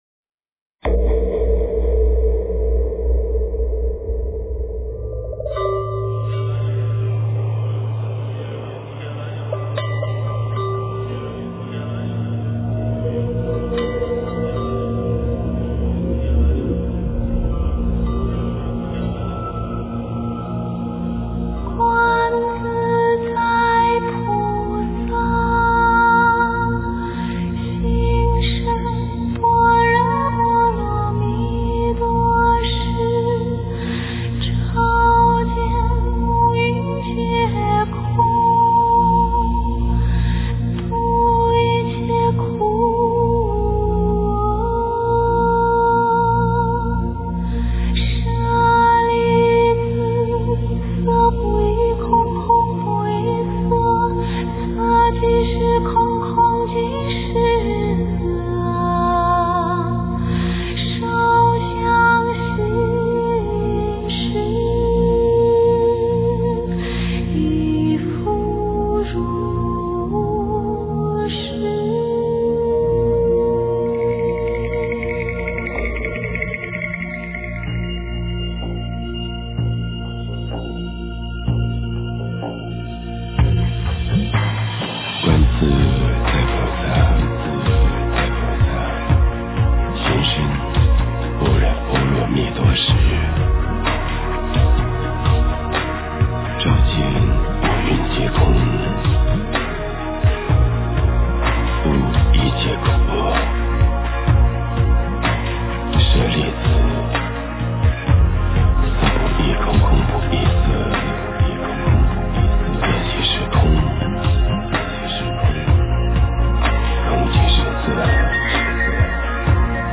佛音 诵经 佛教音乐 返回列表 上一篇： 大悲咒 下一篇： 心经 相关文章 风的呼吸--王俊雄 风的呼吸--王俊雄...